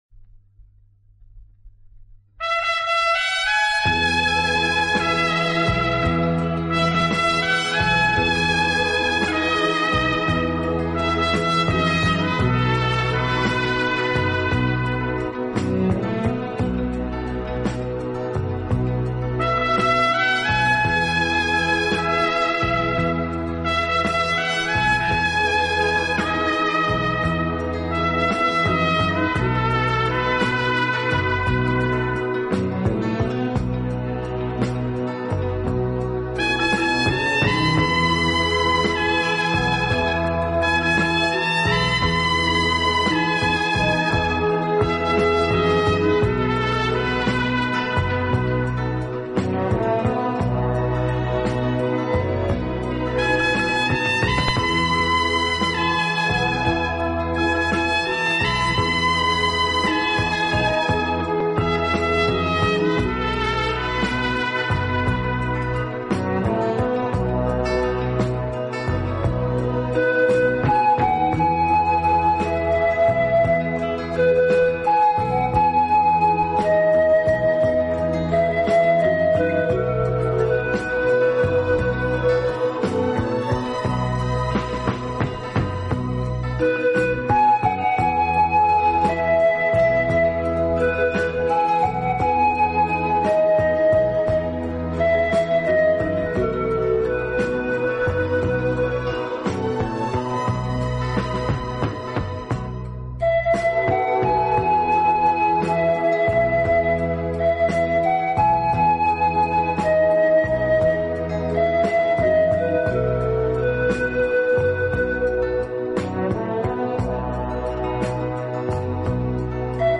乐队以弦乐为中坚，演奏时音乐的处理细腻流畅，恰似一叶轻舟，随波荡